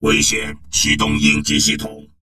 文件 文件历史 文件用途 全域文件用途 Enjo_skill_03.ogg （Ogg Vorbis声音文件，长度2.2秒，107 kbps，文件大小：29 KB） 源地址:地下城与勇士游戏语音 文件历史 点击某个日期/时间查看对应时刻的文件。